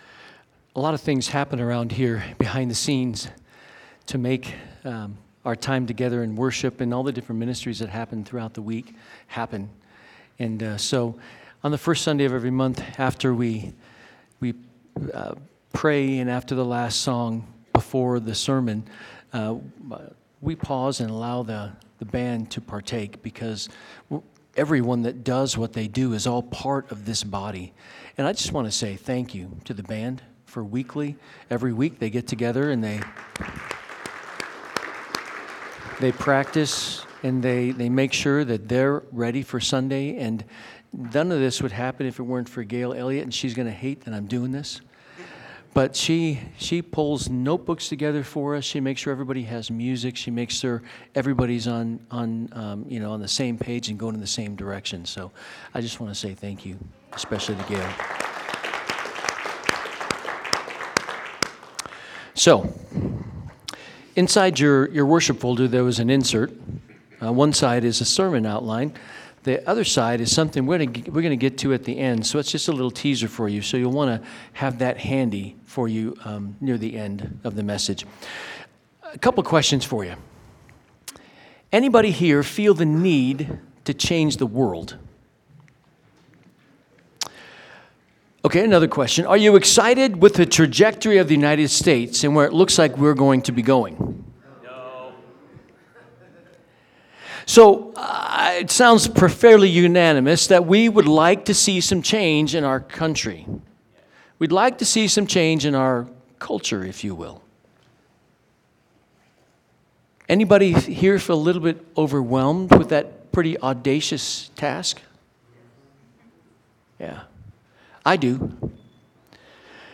Sermons | First Baptist Church of Golden